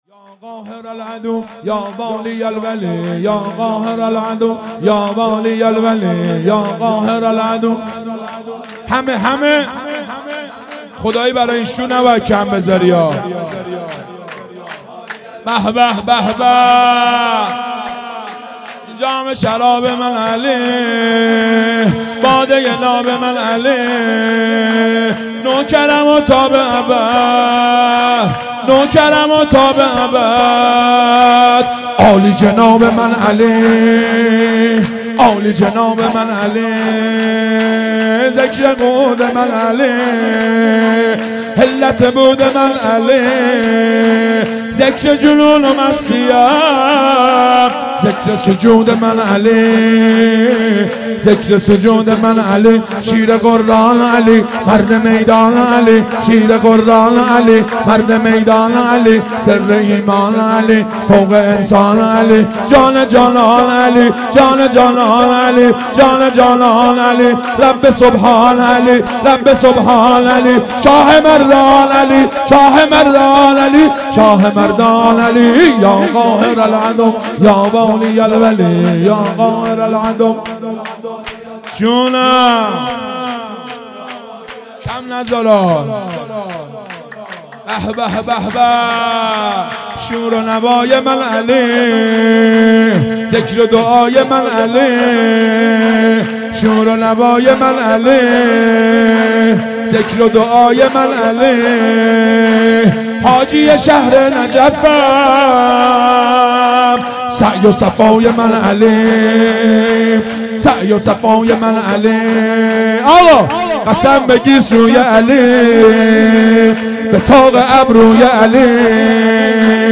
هيأت یاس علقمه سلام الله علیها
یا قاهر العدو یا والی الولی _ شور
ولادت امام باقر علیه السلام